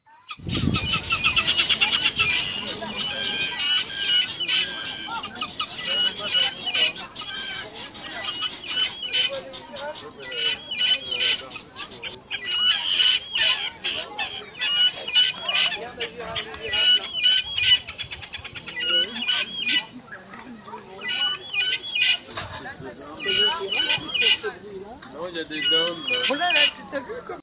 Pintades vulturines: un échantillon
Un mix des sons émis par les pintades: alertes, appels
pintade-tout.mp3